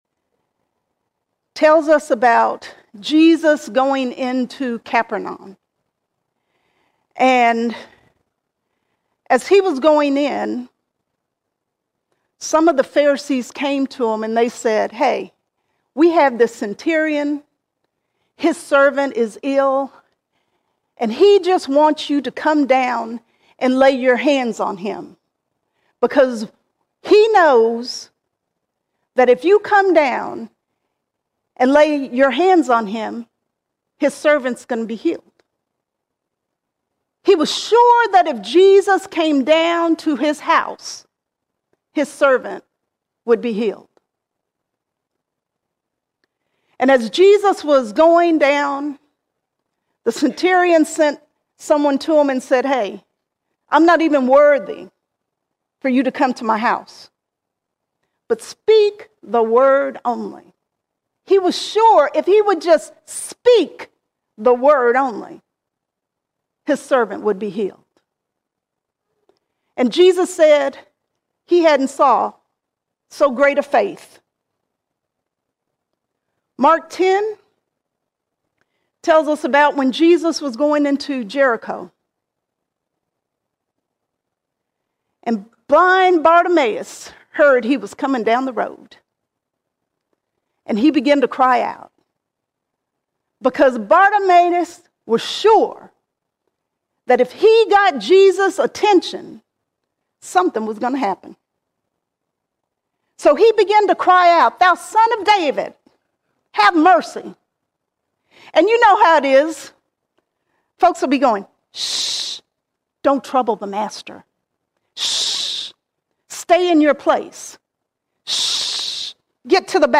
7 April 2025 Series: Sunday Sermons All Sermons A Surety A Surety No matter what life brings, we have a steadfast assurance in Jesus Christ.